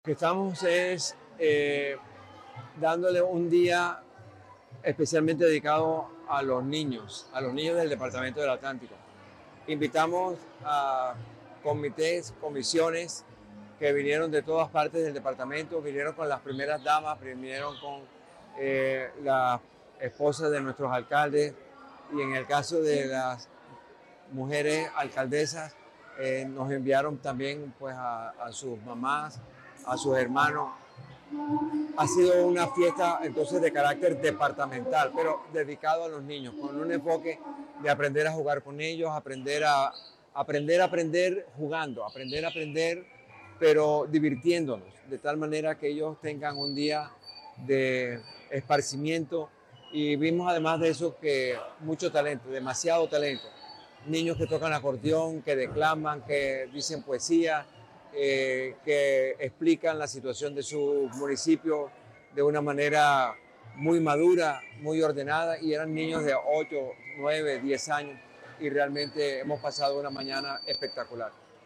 Eduardo Verano – Gobernador del Atlántico
Audio-Eduardo-Verano-Gobernador-del-Atlantico-1-1.mp3